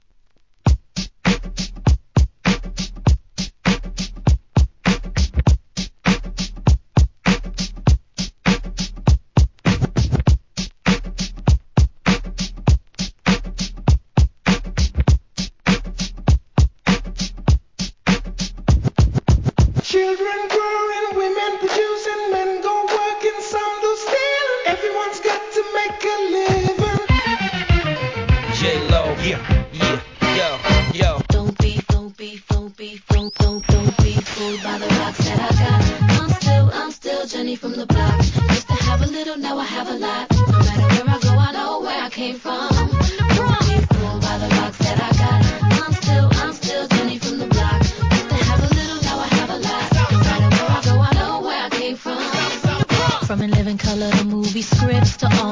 HIP HOP/R&B
使いやすく、さらにフロア仕様にリミックスした大人気のシリーズ第11弾!!